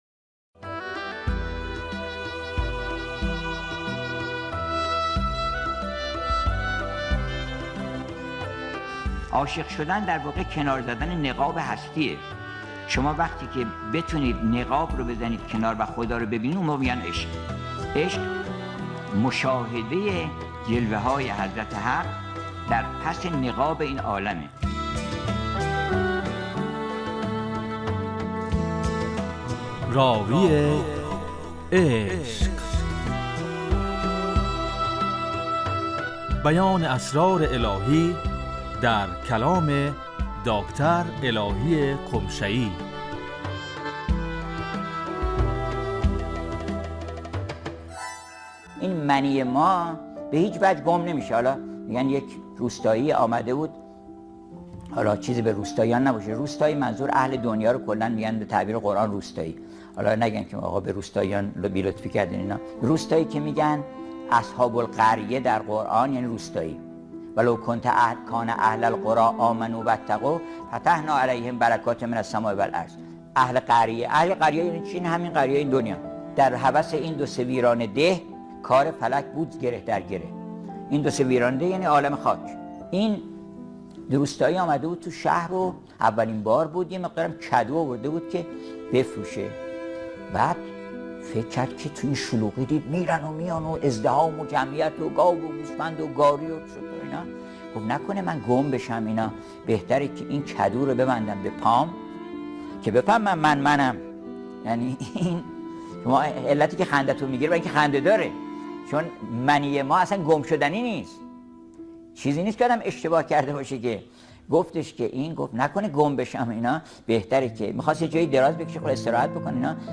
راوی عشق - - بیان اسرار الهی در کلام دکتر الهی قمشه ای